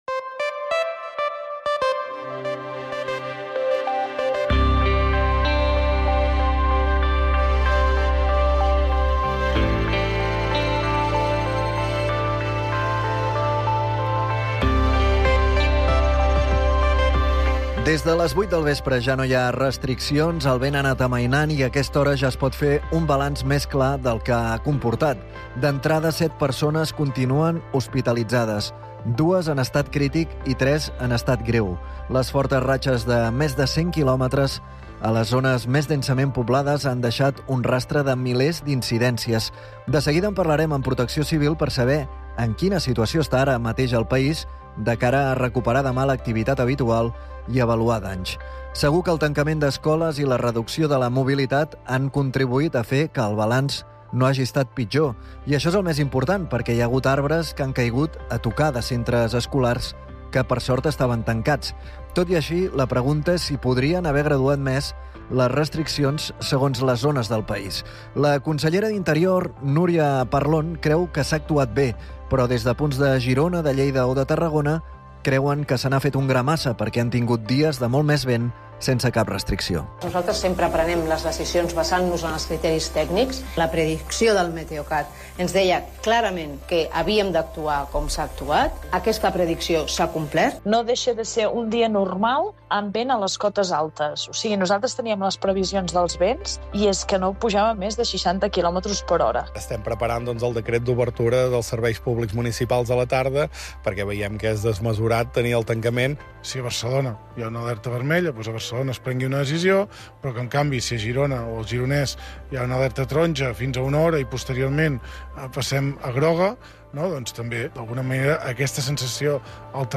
Fem balanç de l'espisodi de fortes ratxes de vent, que s'ha tancat amb milers d'incidències i set persones hospitalitzades. En parlem amb Sergio Delgado, subdirector general de Programes de Protecció Civil.